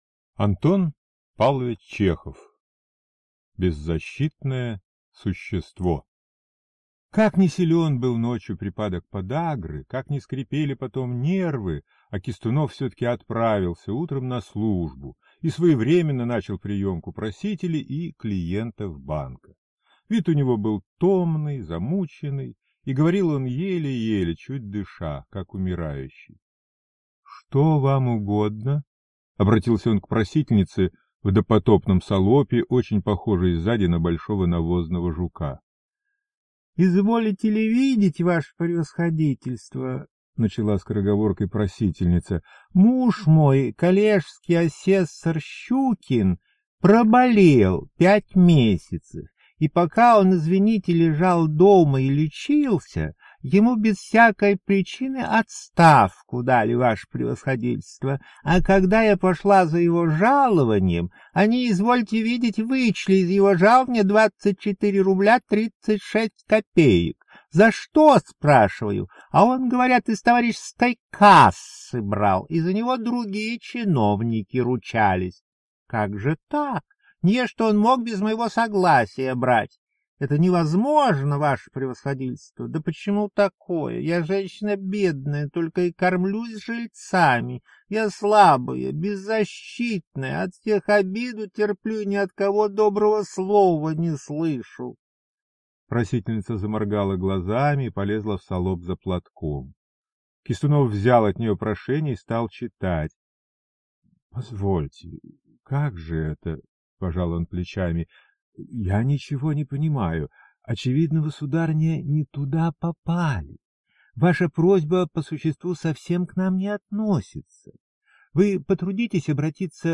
Аудиокнига Беззащитное существо | Библиотека аудиокниг